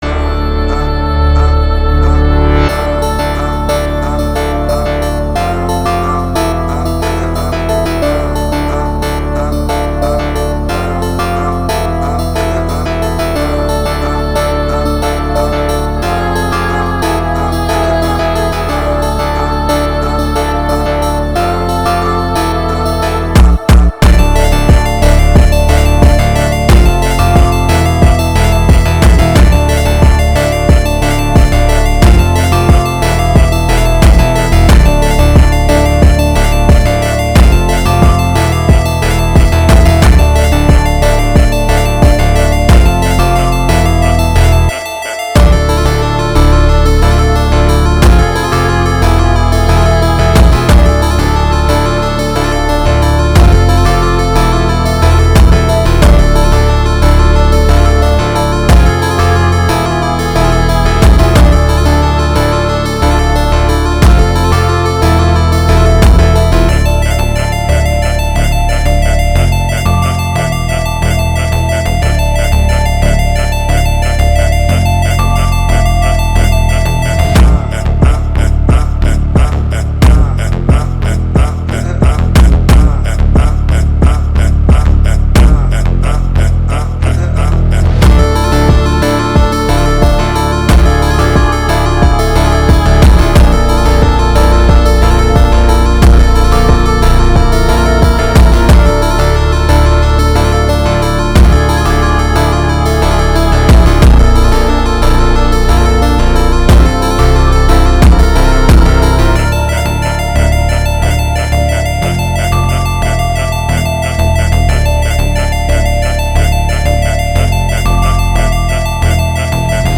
(Phonk)